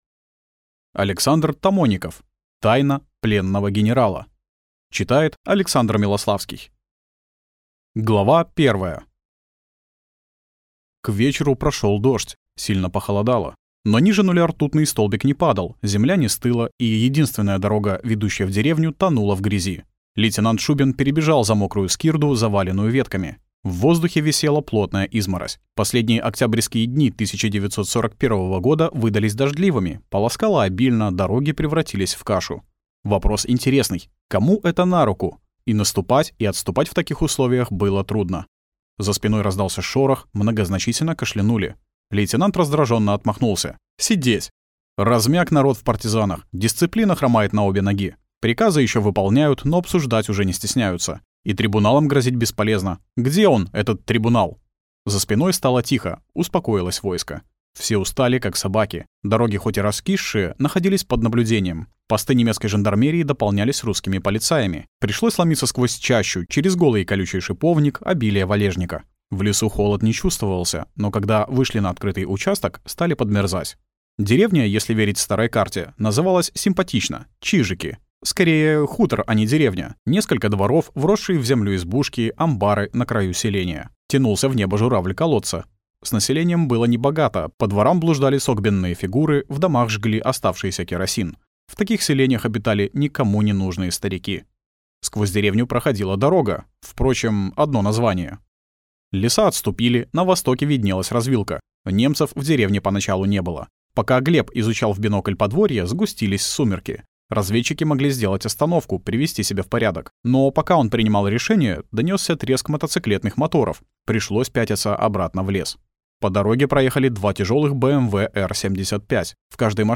Аудиокнига Тайна пленного генерала | Библиотека аудиокниг